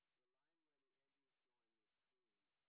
sp22_white_snr10.wav